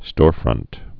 (stôrfrŭnt)